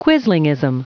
Prononciation du mot quislingism en anglais (fichier audio)
Prononciation du mot : quislingism